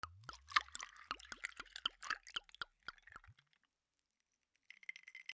ブランデーグラス 氷(ロックアイス) 水を注ぐ
酒 バー